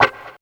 137 GTR 8 -L.wav